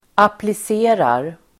Uttal: [aplis'e:rar]